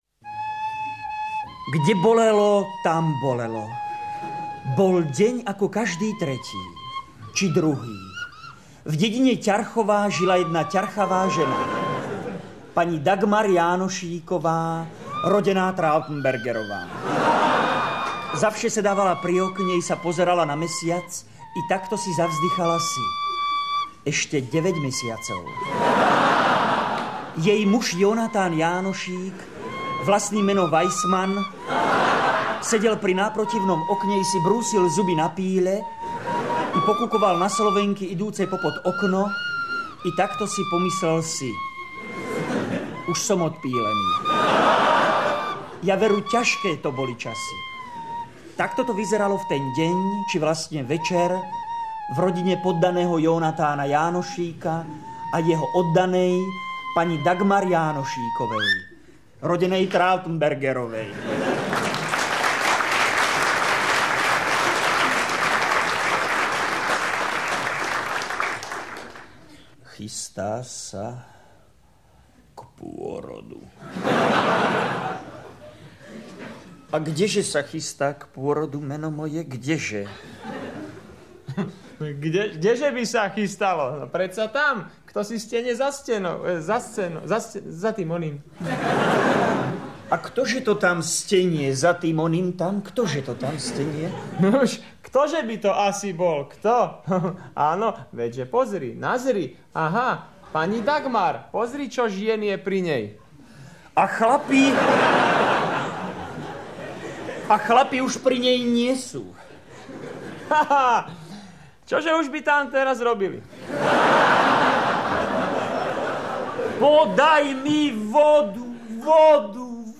Ukážku rozhlasovej hry z roku 1970 si vypočuj až v prípade, keď nebudeš vedieť doplniť všetky slová.